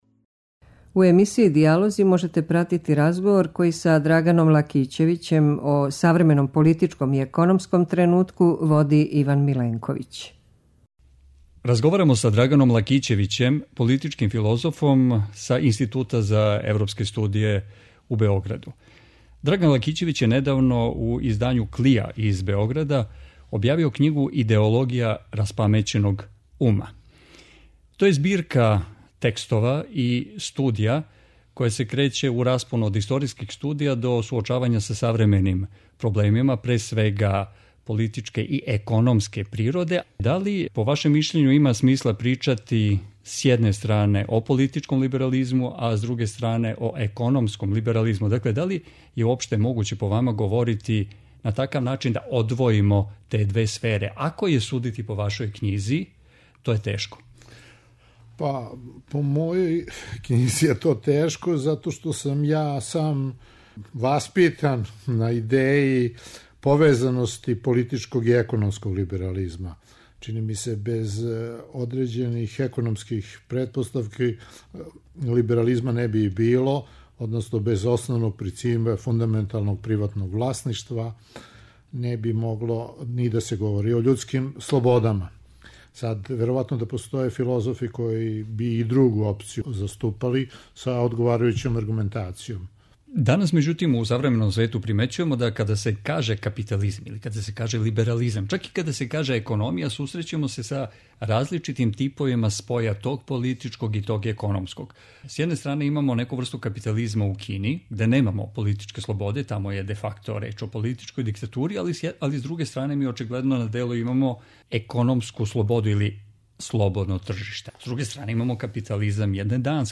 У емисији ДИЈАЛОЗИ можете пратити разговор који је